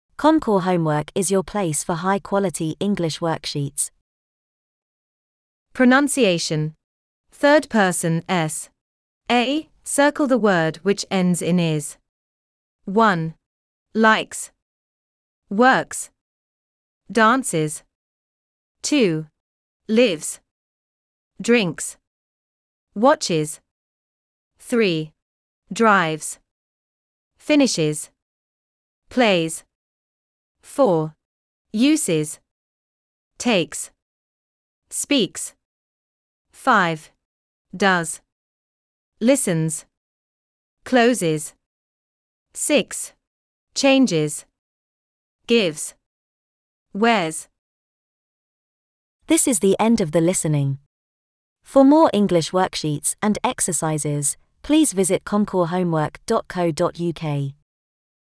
Pronunciation: third person -s a) Circle the word which ends in /ız/.